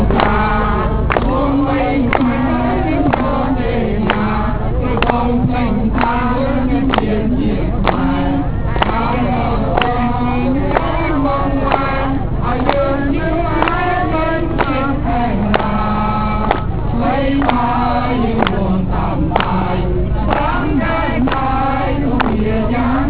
Le KUP à la manifestation Les Jeunes Khmers (LJK) / CFC du 23/02/2003 Sound of demonstration 01 (wav file) Sound of demonstration 02 (wav file) Sound of demonstration 03 (wav file)